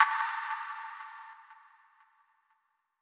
Snr (Metro).wav